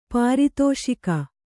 ♪ pāri tōṣika